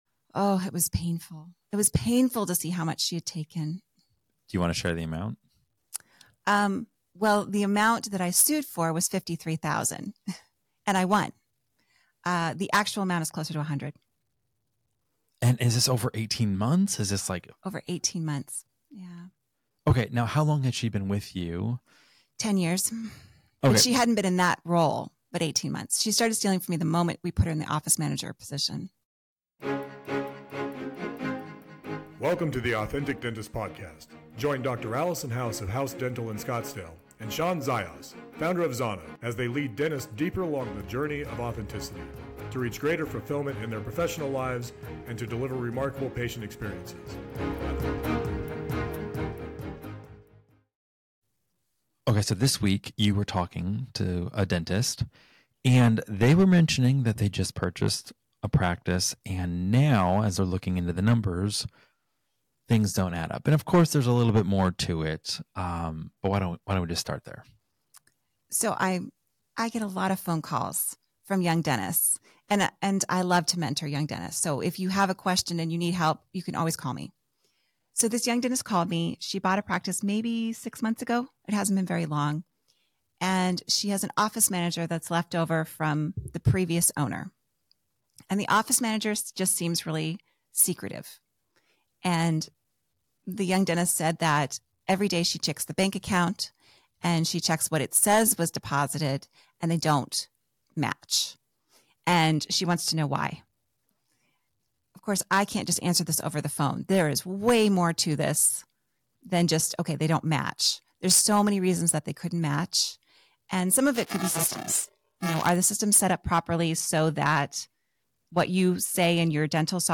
The hosts also discuss the challenges of trusting employees, especially in the era of remote work, and the difficulty of balancing trust with appropriate oversight.